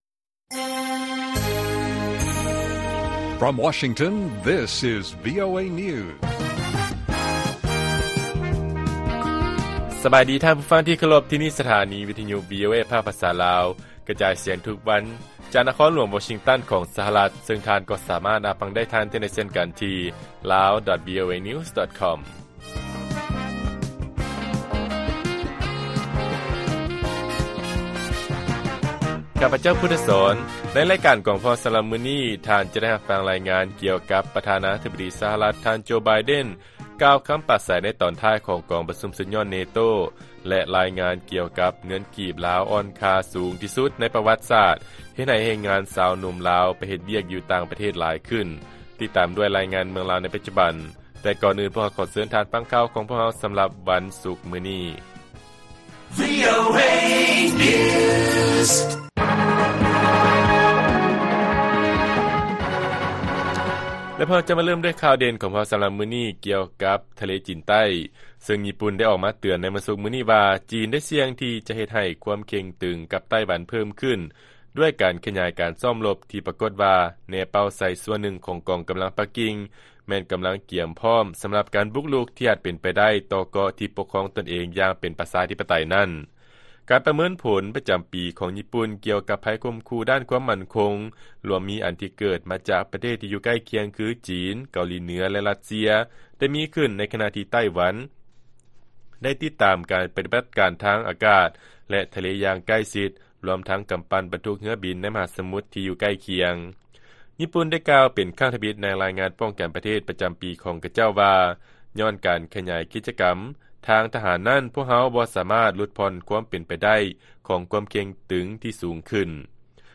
ລາຍການກະຈາຍສຽງຂອງວີໂອເອ ລາວ: ເງີນກີບລາວອ່ອນຄ່າສູງທີ່ສຸດໃນປະຫວັດສາດ ເຮັດໃຫ້ເເຮງງານຊາວໜຸ່ມລາວໄປເຮັດວຽກຢູ່ຕ່າງປະເທດຫຼາຍຂຶ້ນ